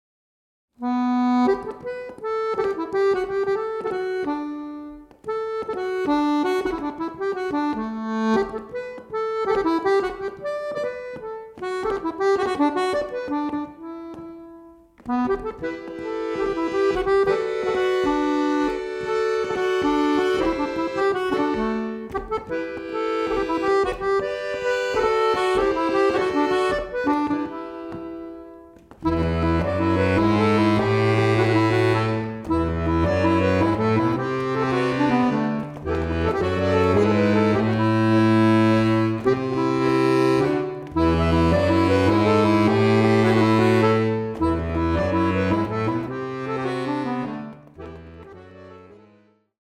Free-bass accordion, stomp-box, and singing